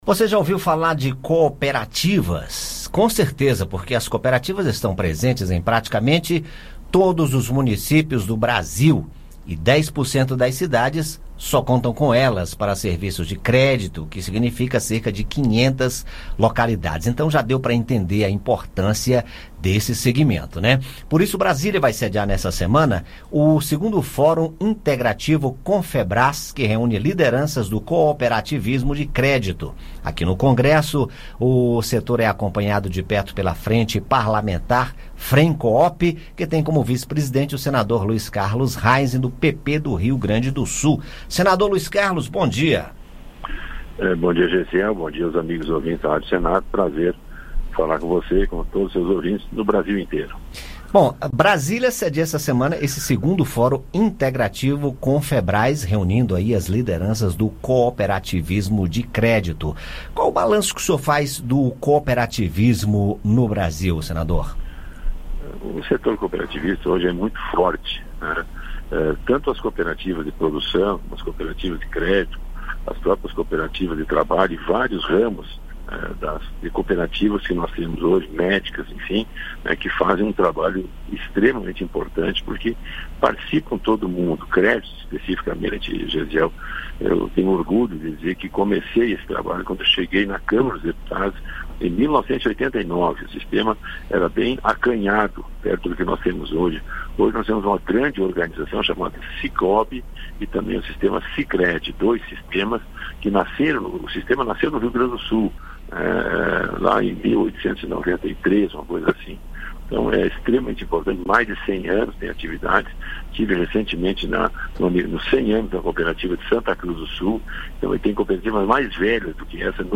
O senador Luis Carlos Heinze (PP–RS) lembra que o setor tem mais de cem anos de atividades e está cada vez mais forte. O senador conversou com a equipe do Conexão Senado. Ouça o áudio da Rádio Senado, com a entrevista.